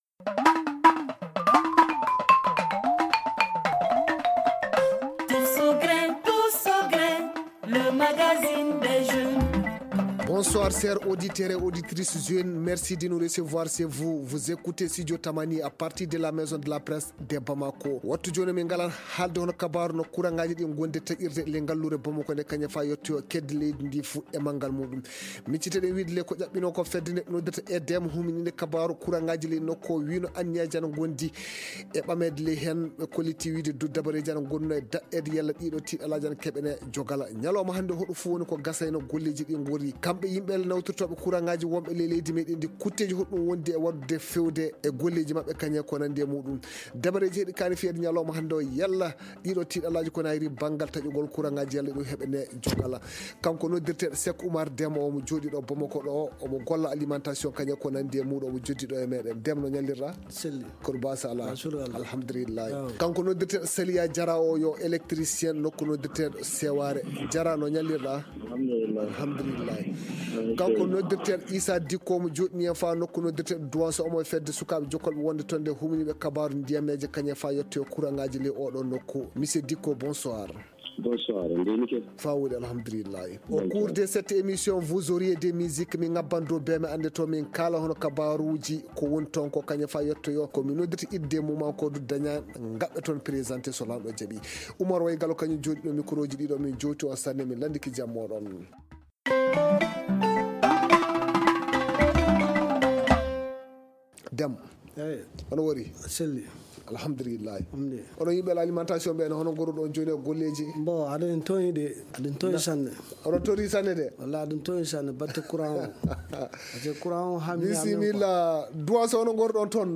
Le « tous au Grin » pose le débat avec comme invités :